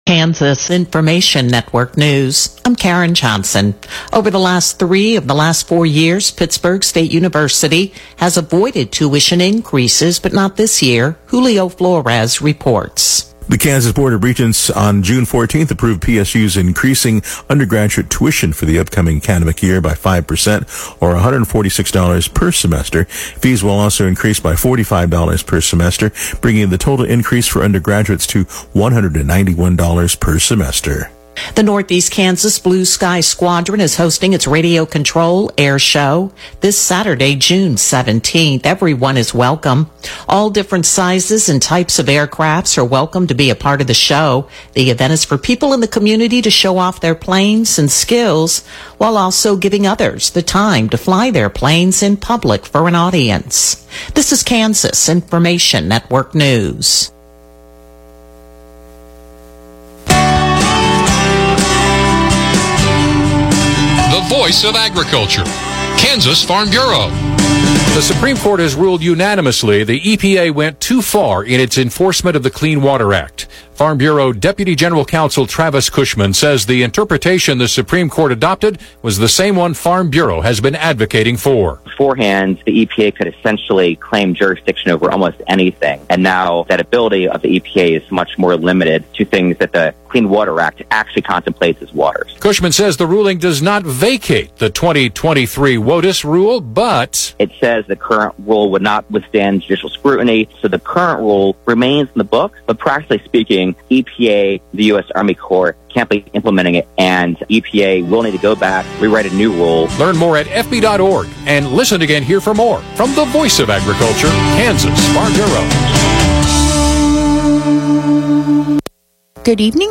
KQNK Evening News